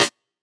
Cx_rim.wav